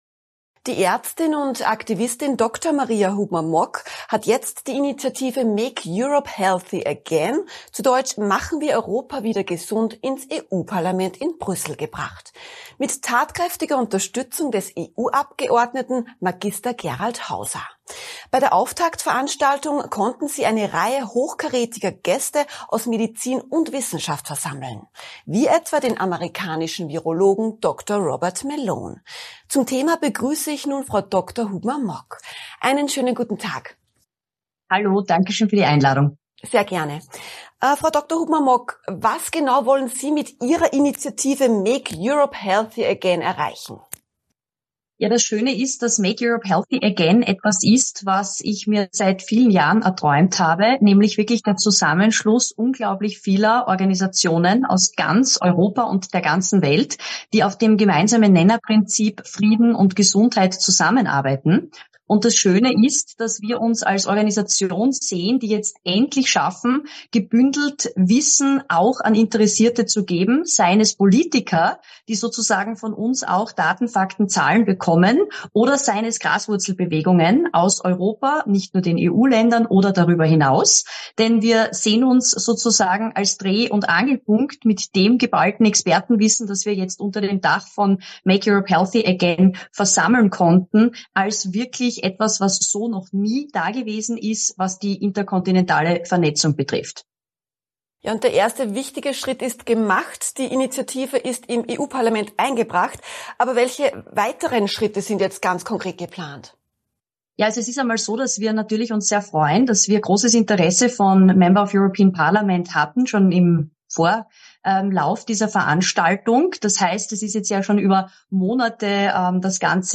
Interview mit AUF1.